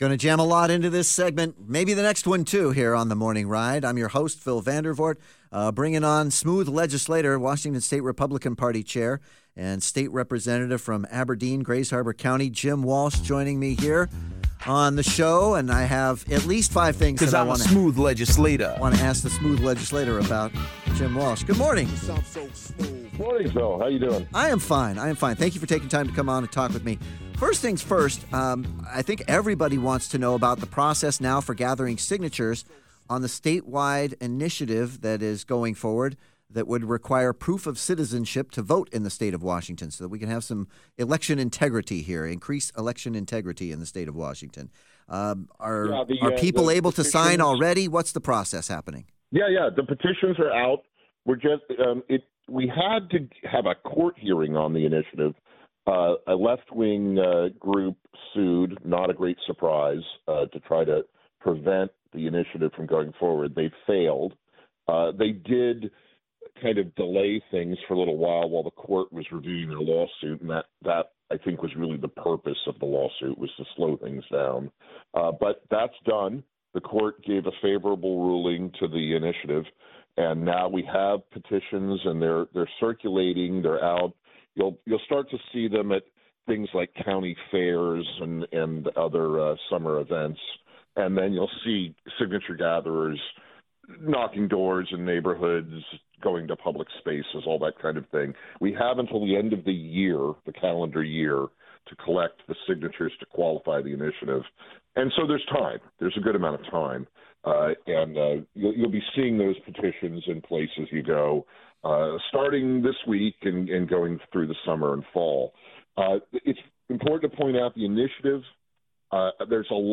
WAGOP Chairman and State Representative Jim Walsh joins The Morning Ride to share how you can sign the initiative that would require voters to provide proof of US citizenship in order to vote in Washington State.